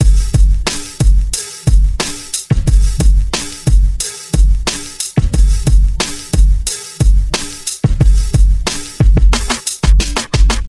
描述：老式风格MP Fl12
标签： 90 bpm Hip Hop Loops Drum Loops 1.80 MB wav Key : Unknown
声道立体声